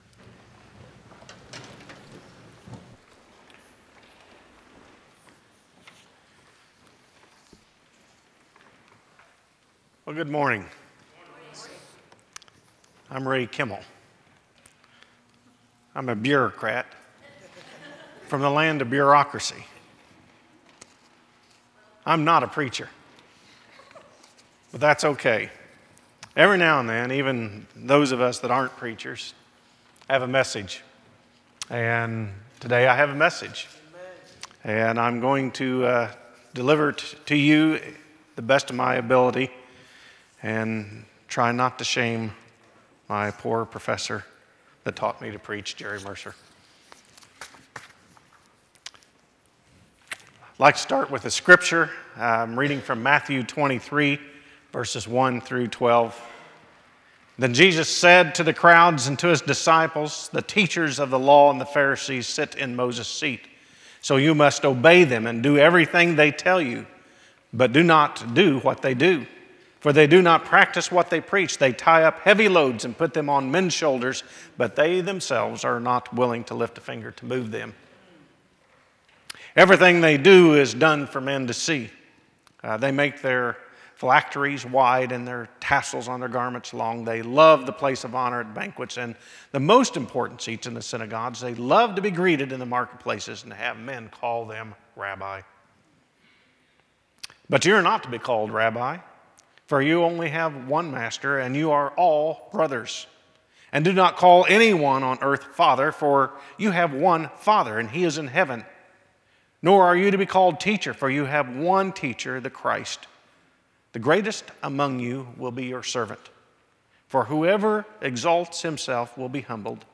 Chapel Services, 2005
Sermons